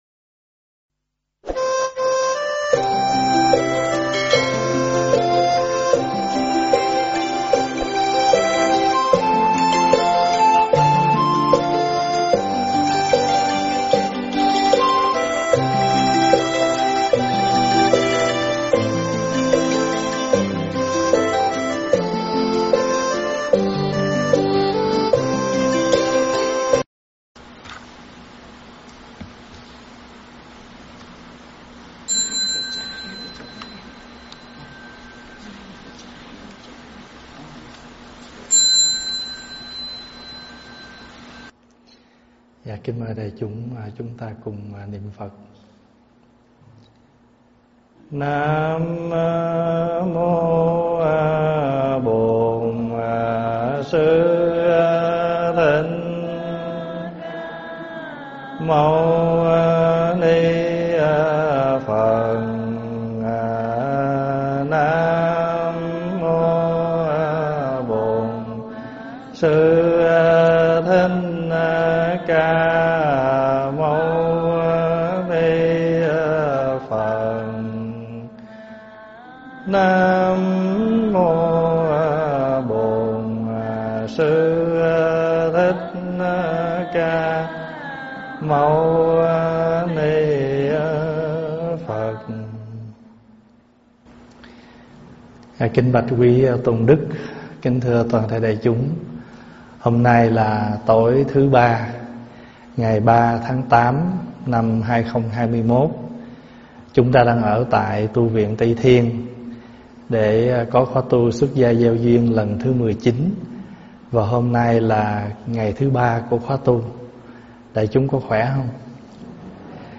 Thuyết pháp
trong khóa tu xuất gia gieo duyên kỳ 19 tại Tv Tây Thiên